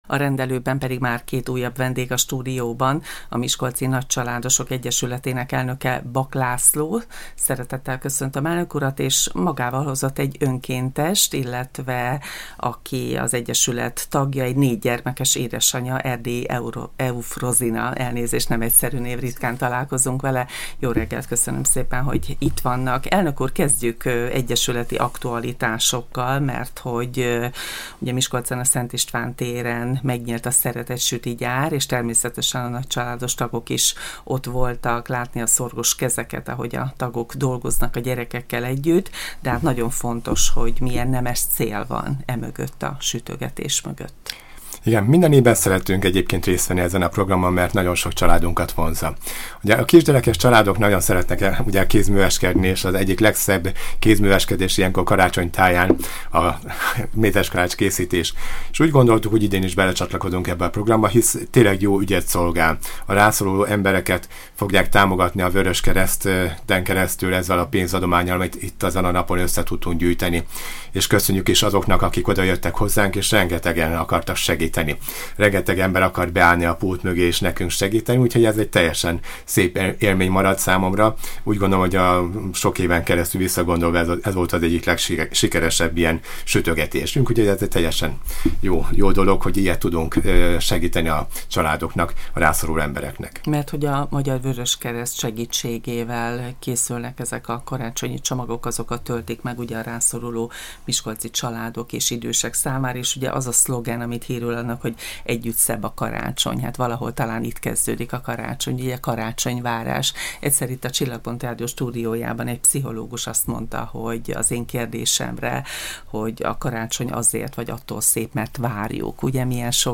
Milyen a karácsonyvárás egy nagycsaládban? S milyen maga az ünnep? Az advent jegyében beszélgettünk